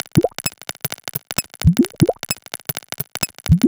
Wuobwuob1 130bpm.wav